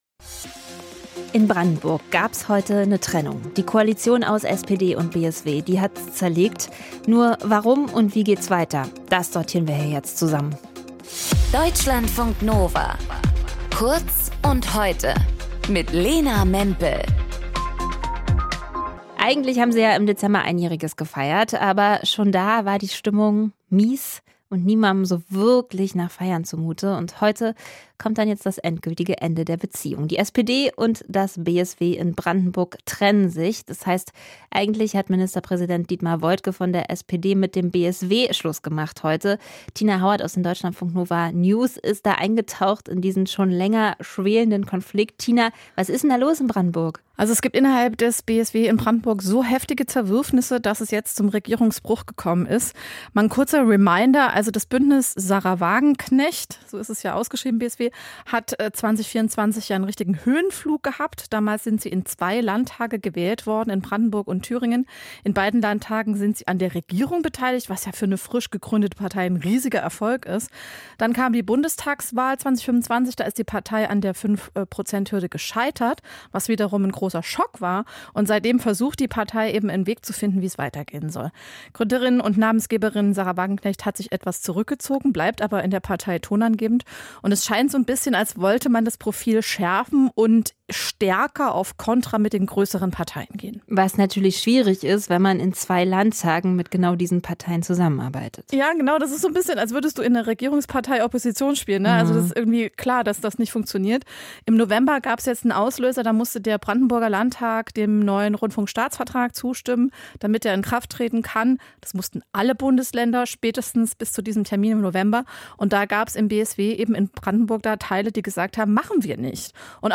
In dieser Folge mit:
Moderation: